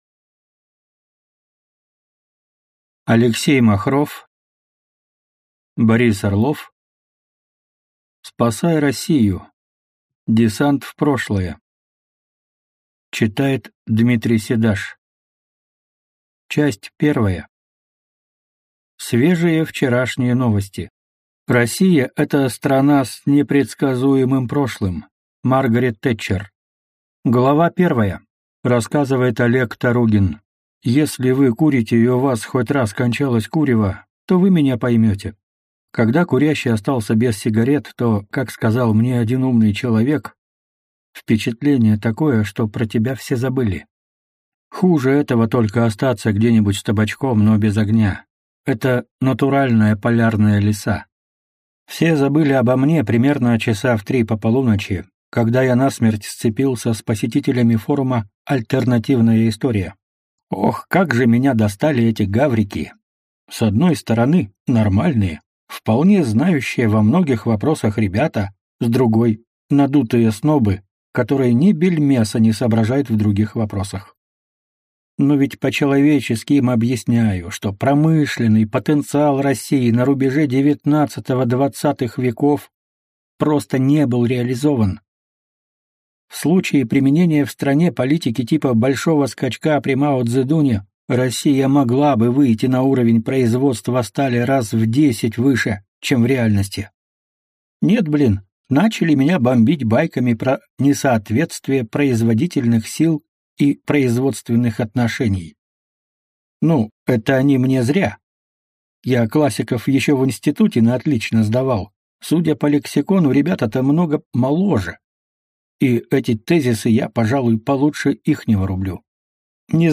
Аудиокнига Корона для «попаданца».